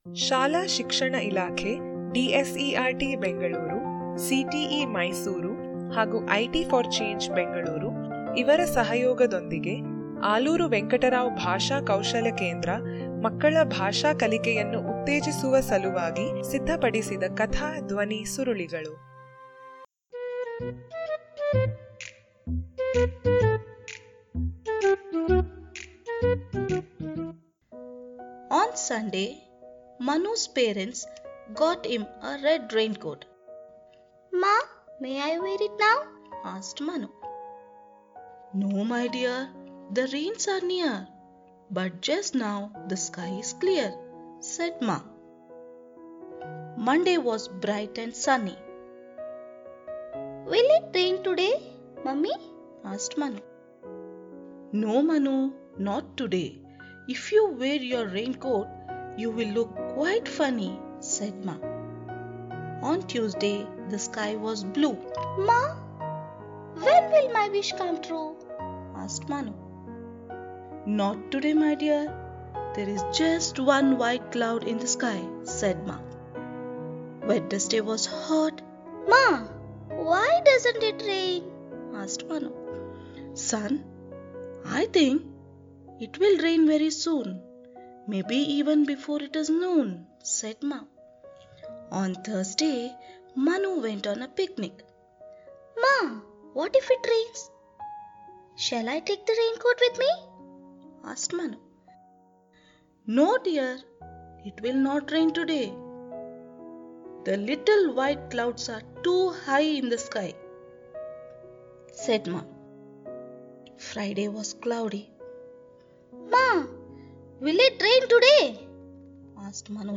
Audio story link